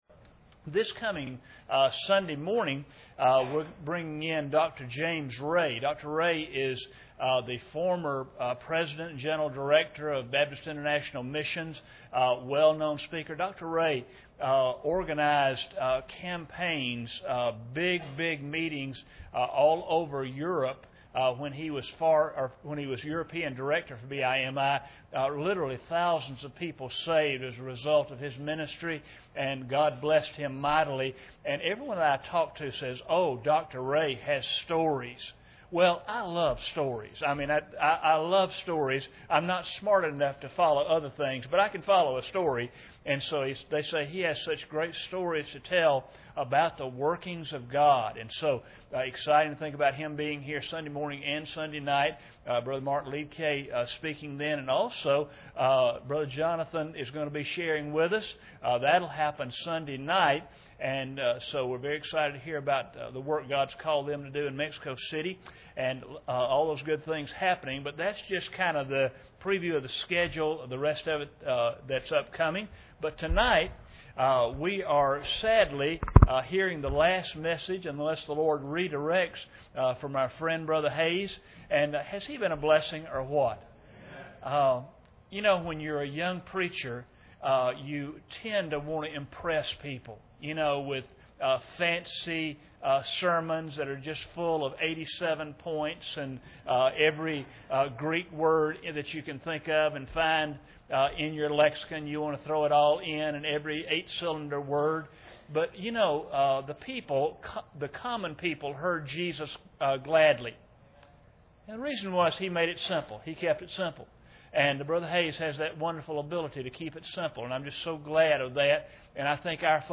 Series: 2011 Missions Conference